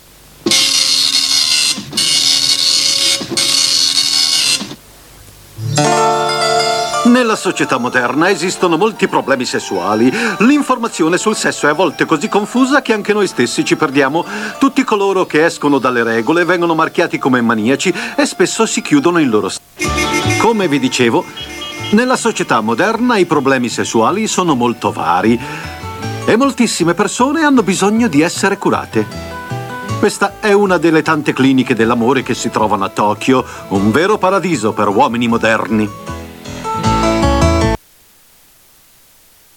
nel cartone animato "La clinica dell'amore", in cui doppia il dott. Sawaru Ogekuri.